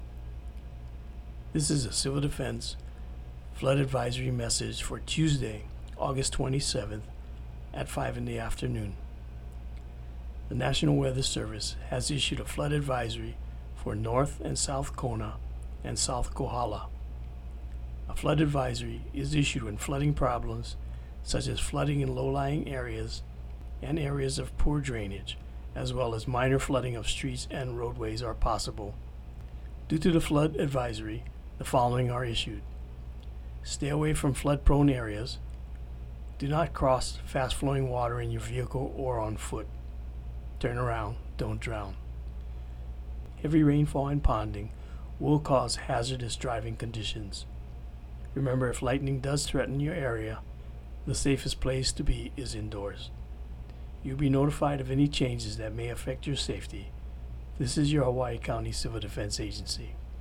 Hawaiʻi County Civil Defense also issued an audio message to alert the public to the advisory.
Flood-Advisory-8-27-19.mp3